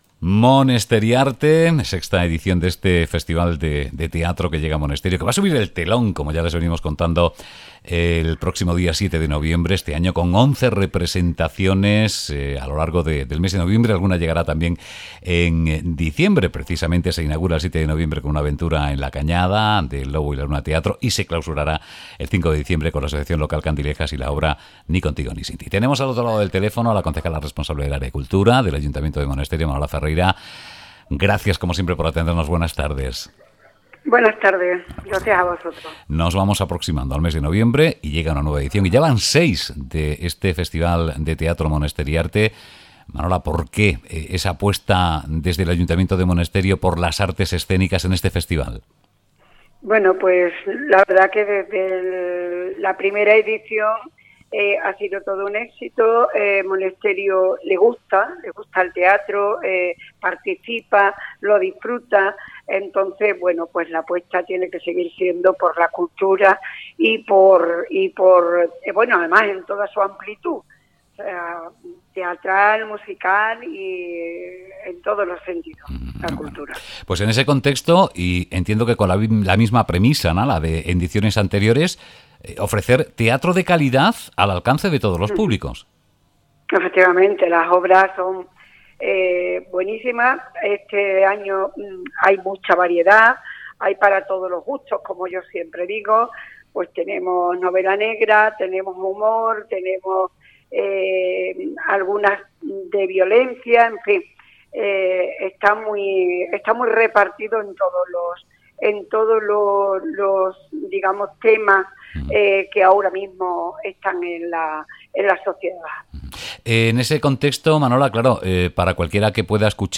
ZlaGeMONESTERIARTEENTREVISTA.mp3